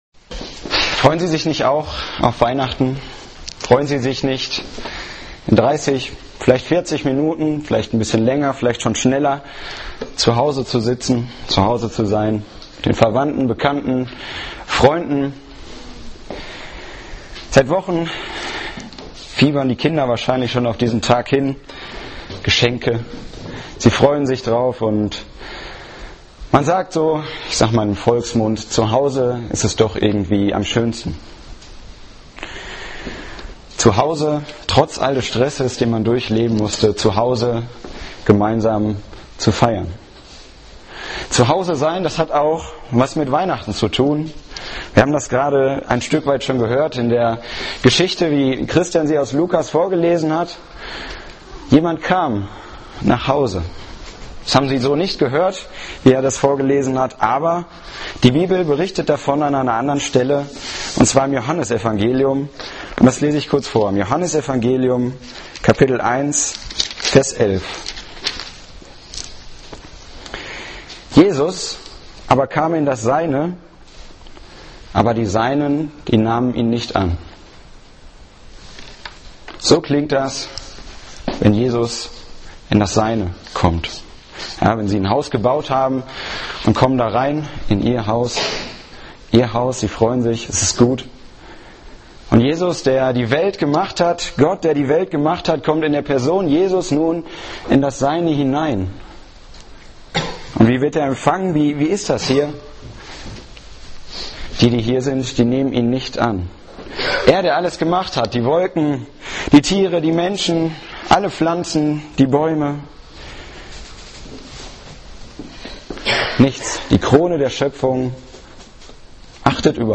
Evangelische Gemeinde Gevelsberg e.V. - Predigten